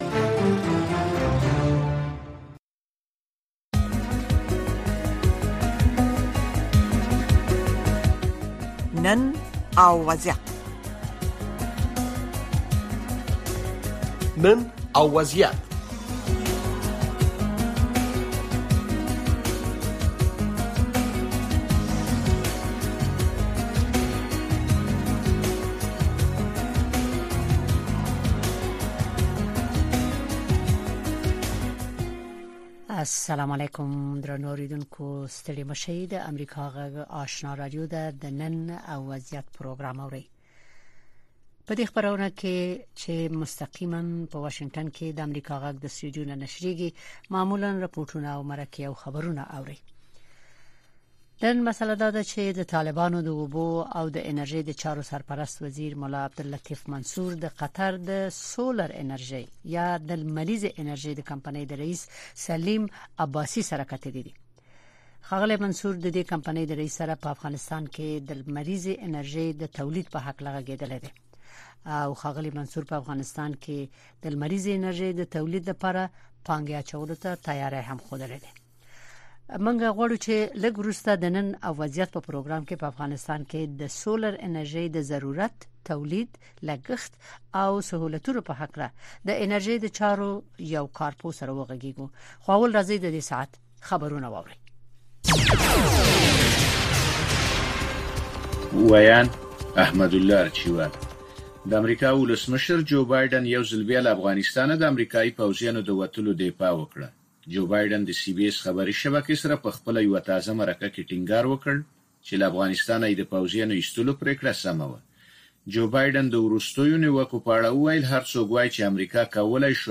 د نړۍ سیمې او افغانستان په روانو چارو او د نن په وضعیت خبرونه، راپورونه، مرکې او تحلیلونه